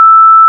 **🔊 SFX PLACEHOLDERS (23 WAV - 1.5MB):**
**⚠  NOTE:** Music/SFX are PLACEHOLDERS (simple tones)
harvest.wav